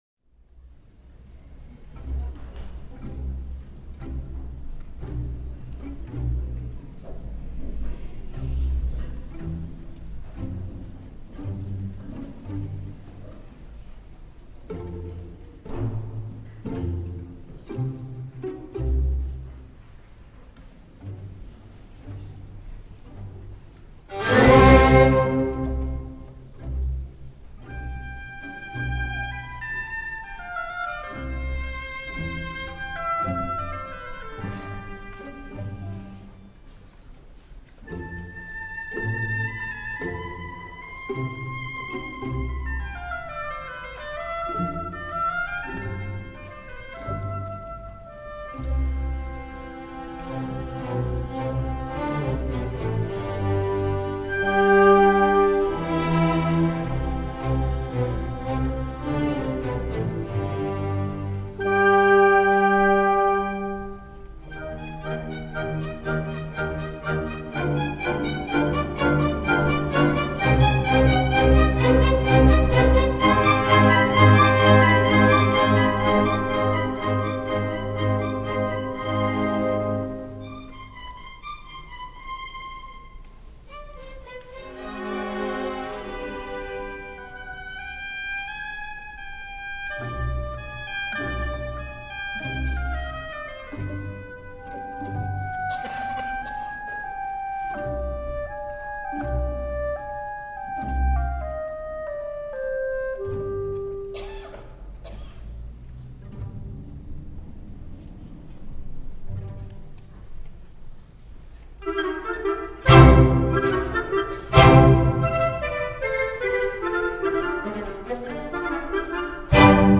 Provincia di Bari, Bari Teatro Piccinni, 10/6/05)